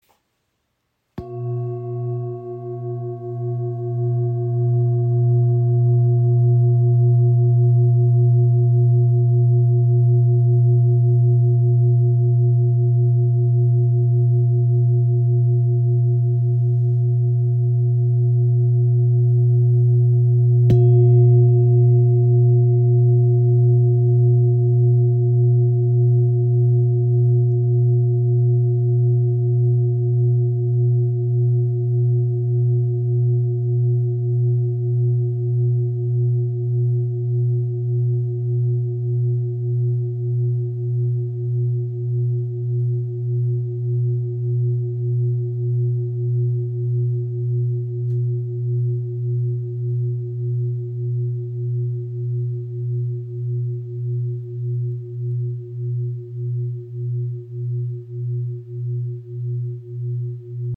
Klangschale mit Shri Yantra | ø 24.5 cm | Ton ~ B (H) | Chiron (120,25 Hz)
• Icon Inklusive passendem rotem Filzschlägel. Gewicht 1516 g
• Icon Zentrierender Klang im Ton ~ B (H) | Planetenton Chiron (120,25 Hz)
Ihr obertonreicher Klang im Ton B (H)  ist klar und zentrierend.
Klanglich bringt er oft ein Gefühl von Tiefe, Weichheit und stillem Mut mit sich.